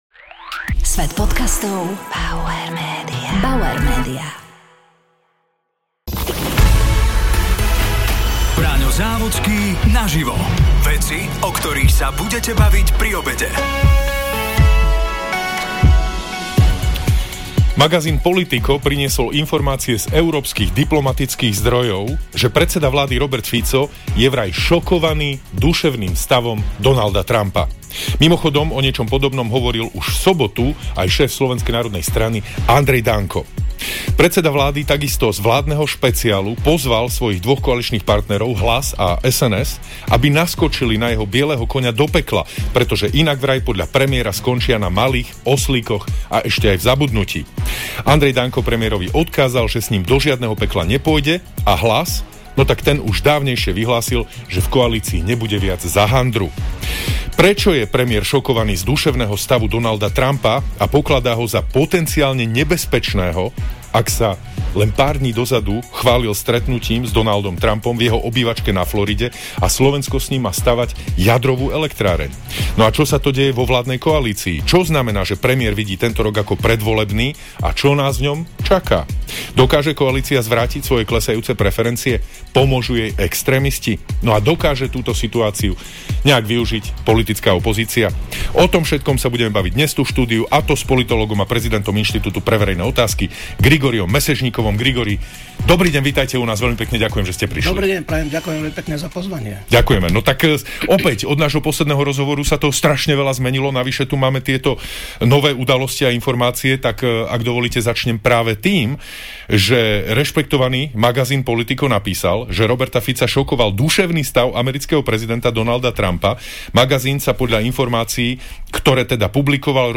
Braňo Závodský sa rozprával s politológom a prezidentom Inštitútu pre verejné otázky Grigorijom Mesežnikovom .